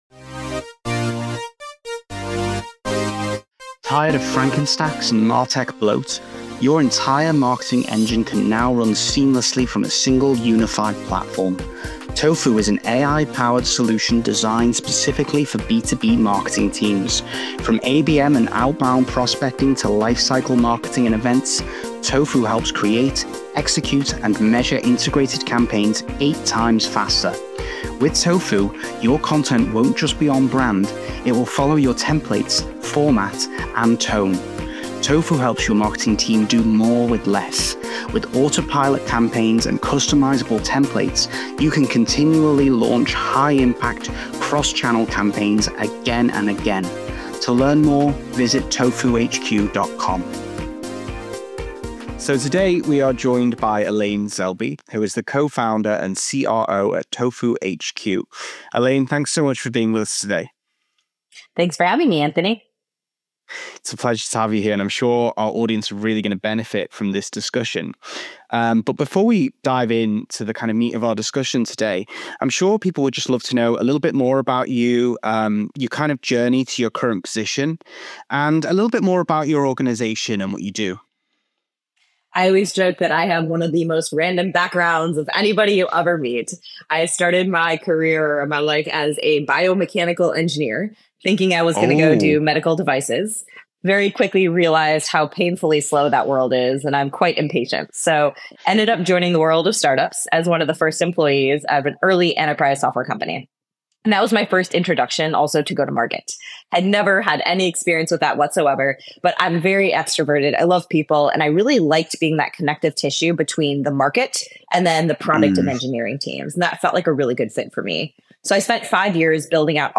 In each episode, we have in-depth conversations with CMOs and top-level marketers from around the world, across every industry and level of experience, in order to get their insights into what it takes to excel at the very top of the marketing hierarchy.